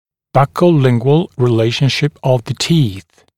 [ˌbʌkəu’lɪŋgwəl rɪ’leɪʃnʃɪp əv ðə tiːθ][ˌбакоу’лингуэл ри’лэйшншип ов зэ ти:с]щечно-язычное соотношение зубов, щечно-язычное положение зубов